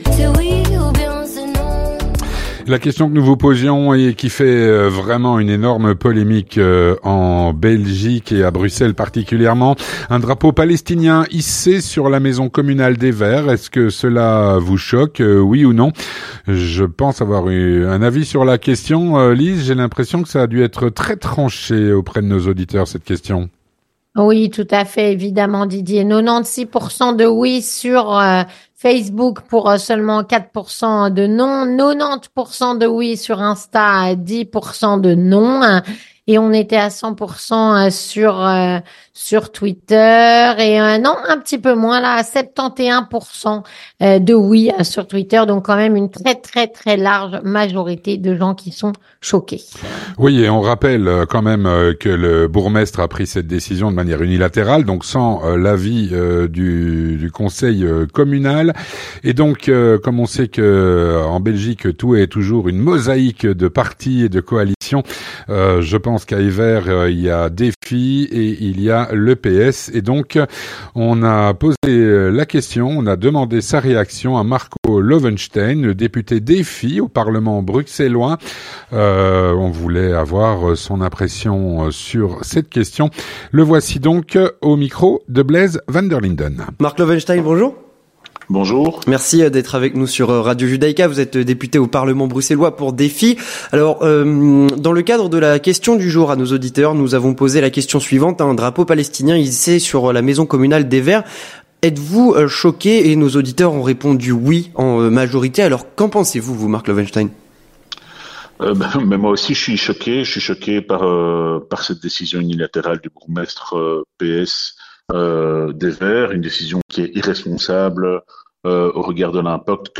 Marco Loewenstein, député Défi au Parlement bruxellois, répond à "La Question Du Jour"